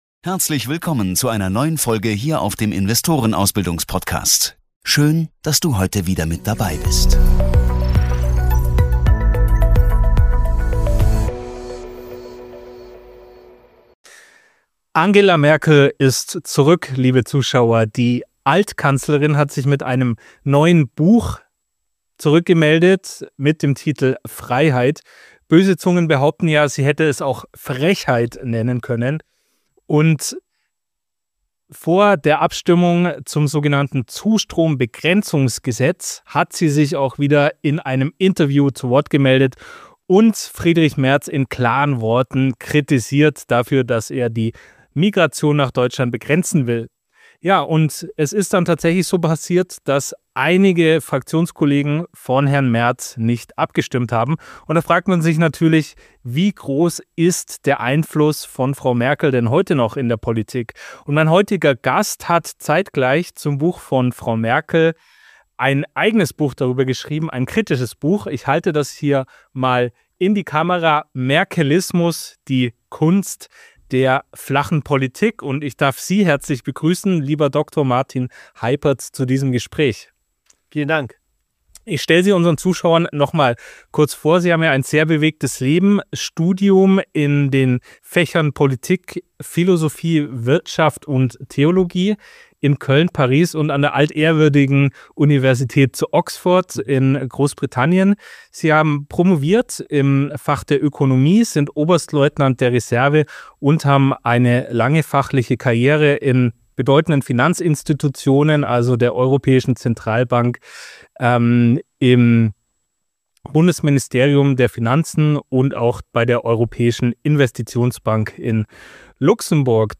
Das Gespräch analysiert die „Sargnägel“ der Merkel-Politik und wirft einen Blick auf mögliche Zukunftsszenarien für die deutsche Politik nach der Bundestagswahl. Das Gespräch wurde wenige Tage vor der Bundestagswahl 2025 aufgezeichnet.